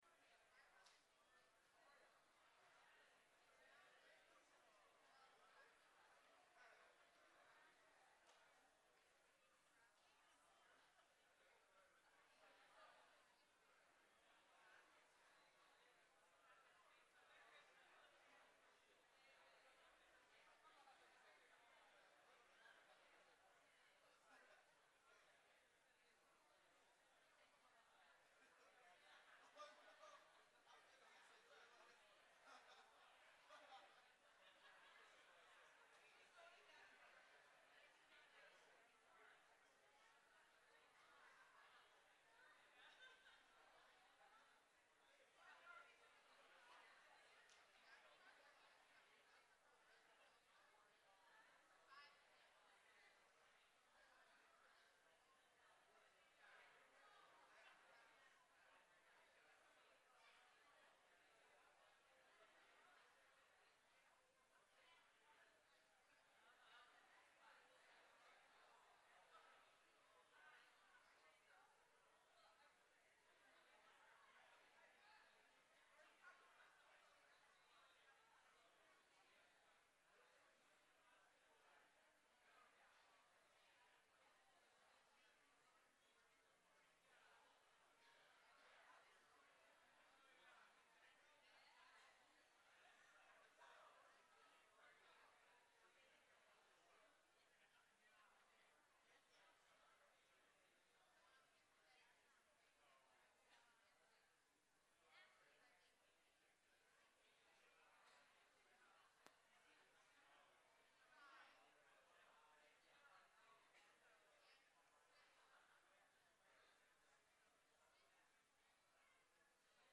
Living Water // Sermon